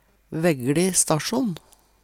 VæggLi stasjsjon - Numedalsmål (en-US)
VæggLi stasjsjon
vaeggli-stassjon-v.mp3